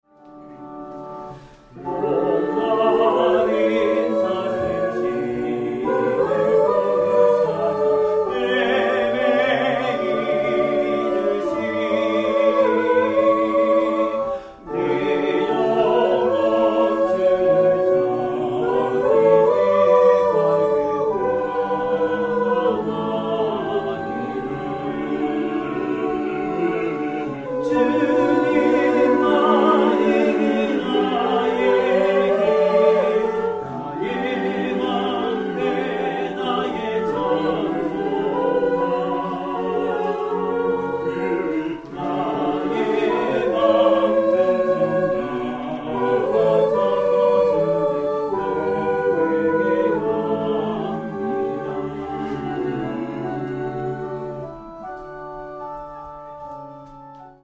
♪聖歌隊練習補助
Tonality = D
練習: 2024/9/1聖歌隊練習 Take2 2回目のみ